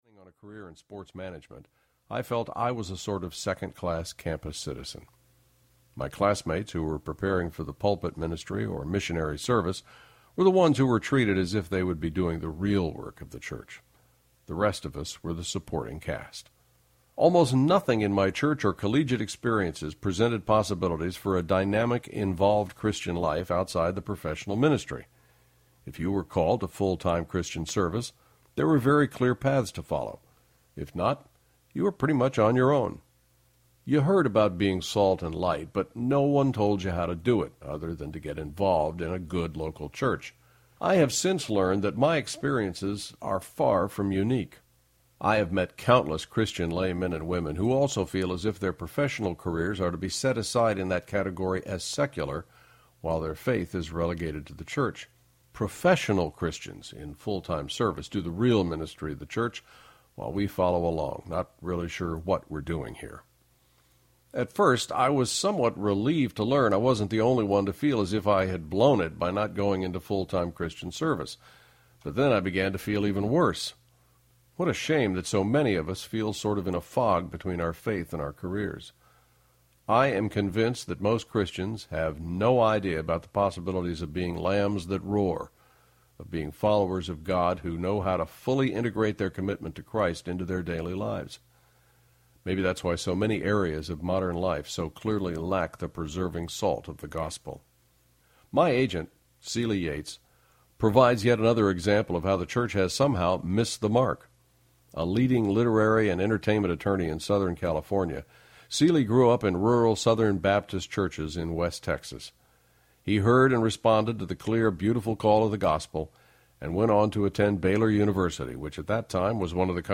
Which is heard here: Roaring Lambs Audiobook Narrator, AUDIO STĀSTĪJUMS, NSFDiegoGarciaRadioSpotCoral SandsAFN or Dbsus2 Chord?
Roaring Lambs Audiobook Narrator